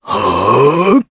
Crow-Vox_Casting3_jp.wav